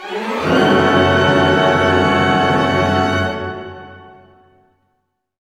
Index of /90_sSampleCDs/Roland L-CD702/VOL-1/ORC_Orch Gliss/ORC_Minor Gliss